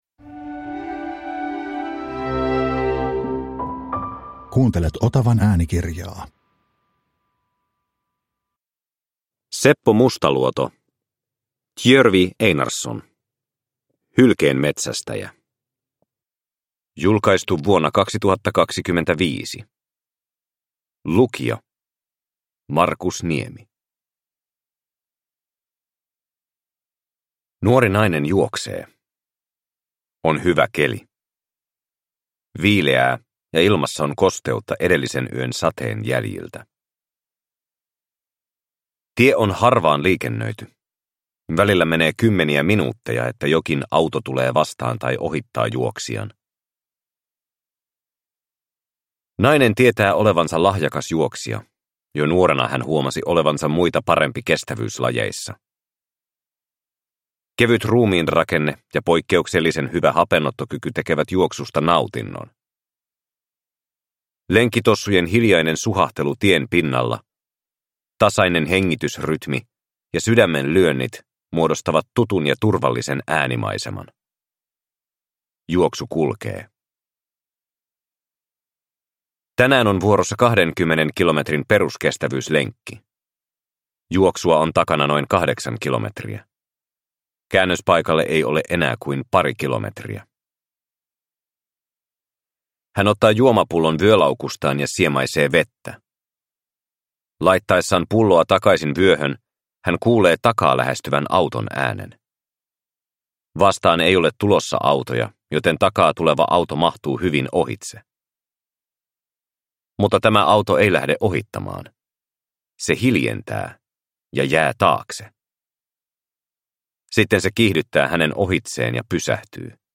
Hylkeenmetsästäjä (ljudbok) av Seppo Mustaluoto